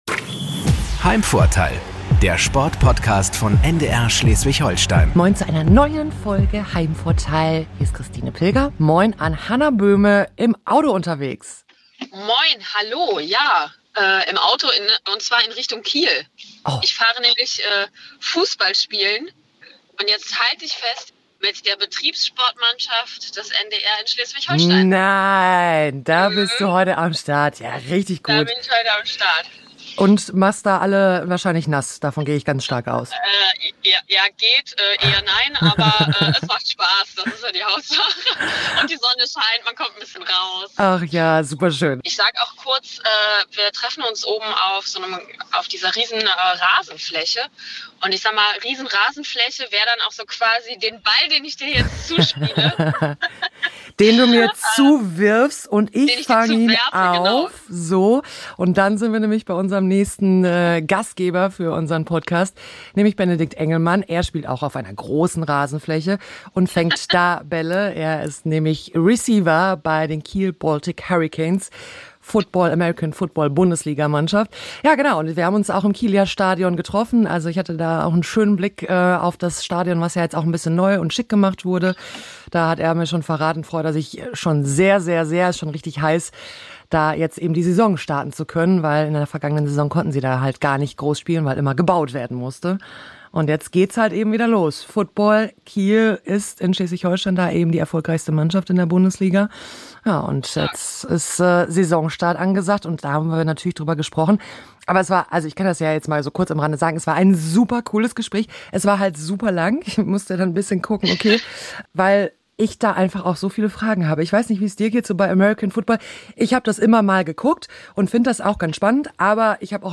Wir sprechen im Kilia-Stadion u.a. über American Football in Deutschland und die Entwicklung der Canes.